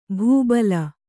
♪ bhū bala